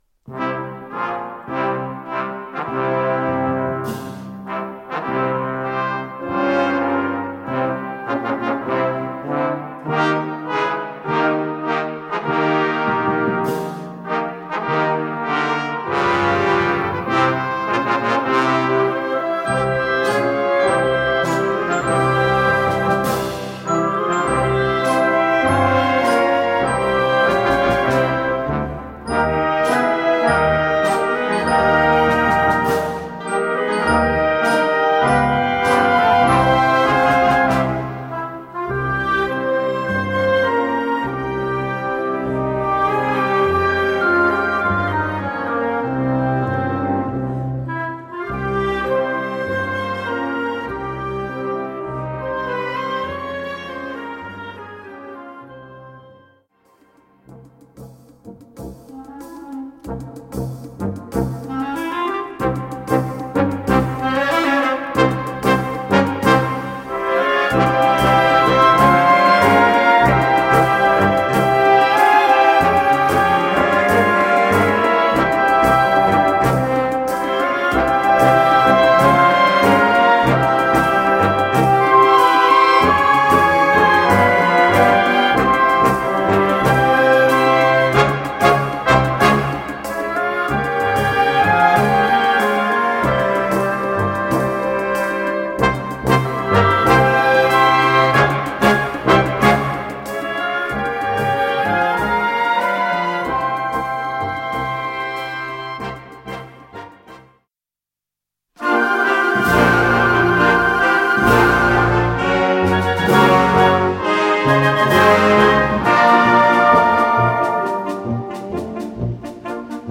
Sous-catégorie Musique de concert
Instrumentation Ha (orchestre d'harmonie)